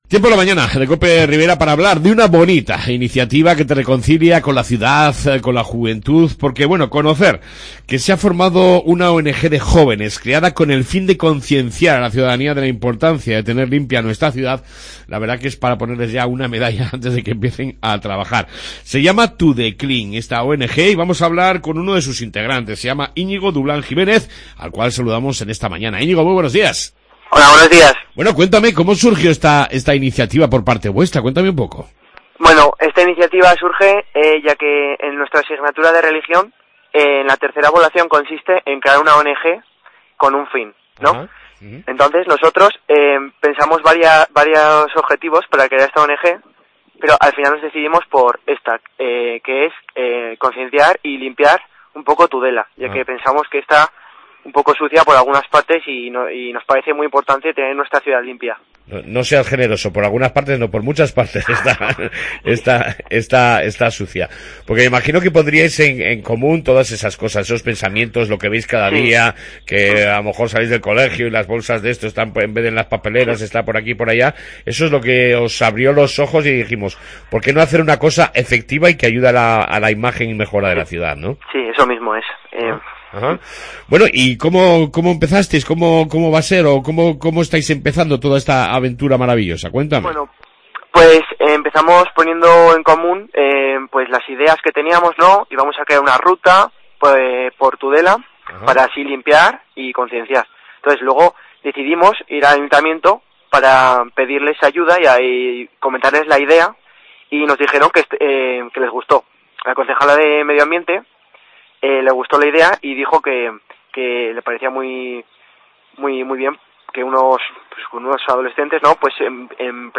Hoy hemos entrevistado a Tudeclean Tudecleantm, una ONG de jóvenes creada con el fin de concienciar a la ciudadanía de la importancia de tener limpia nuestra ciudad.